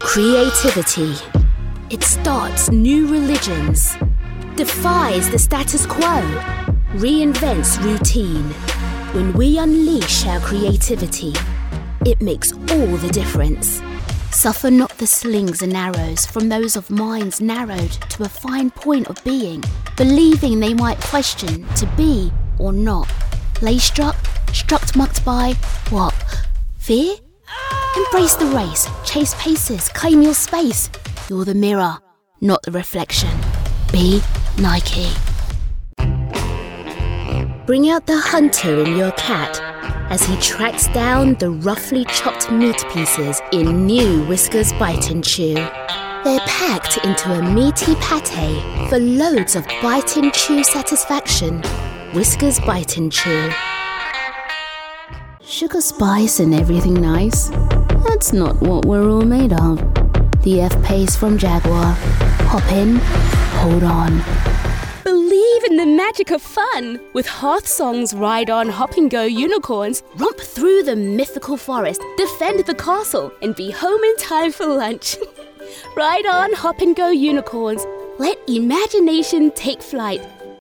Anglais (britannique)
De la conversation
Optimiste
Amical